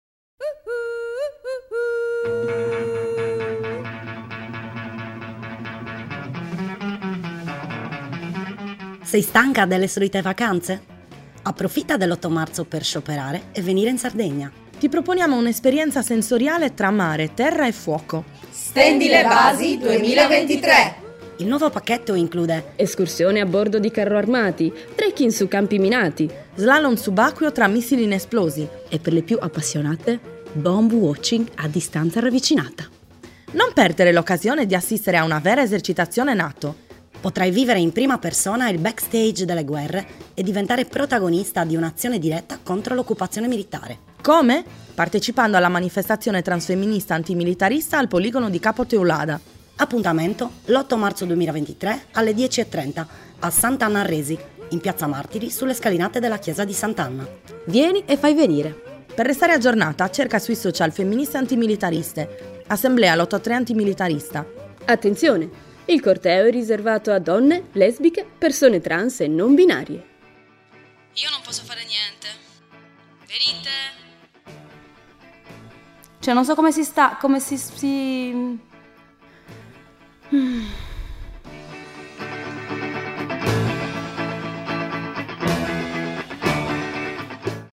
steba_spot_radio23-1.mp3